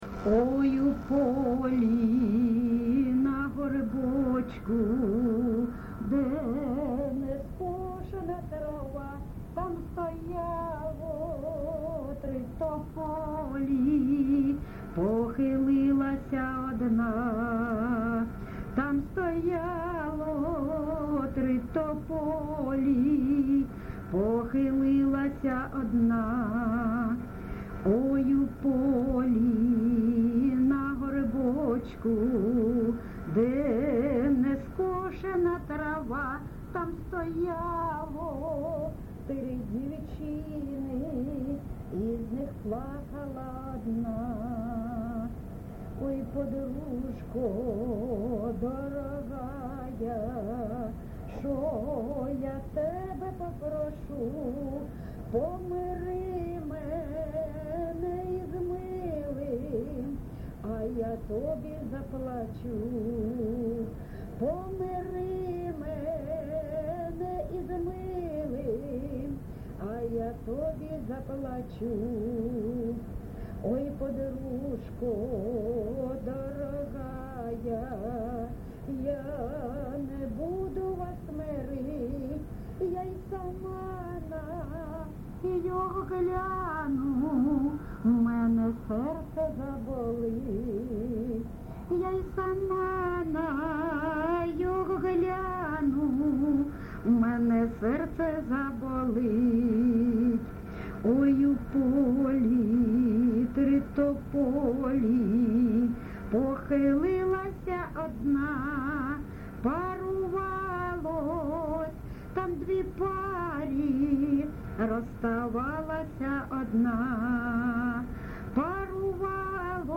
ЖанрПісні з особистого та родинного життя, Сучасні пісні та новотвори
Місце записус. Лозовівка, Старобільський район, Луганська обл., Україна, Слобожанщина